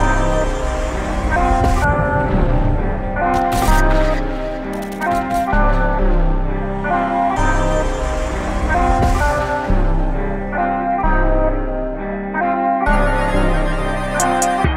THE SCOTTS Loop.wav